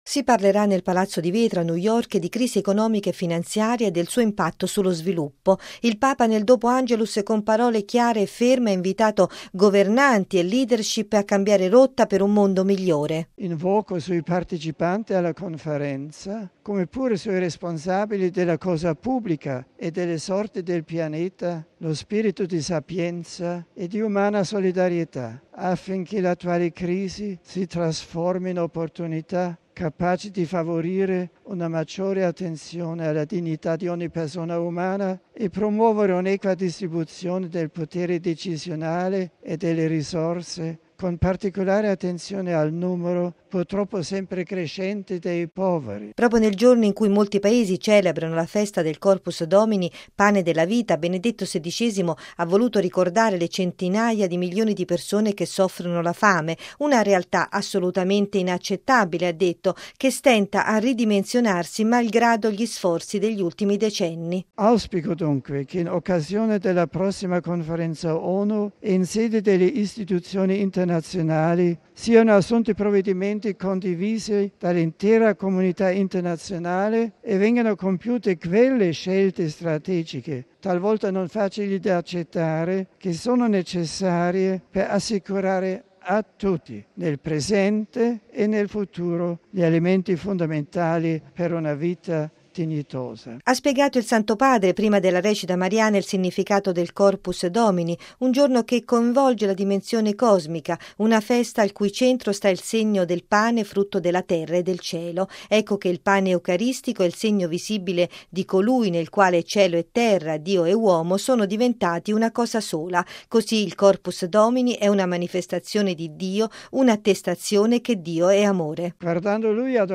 Si parlerà nel Palazzo di Vetro, a New York, di crisi economica e finanziaria e del suo impatto sullo sviluppo: il Papa nel dopo Angelus, con parole chiare e ferme ha invitato governanti e leadership a cambiare rotta per un mondo migliore.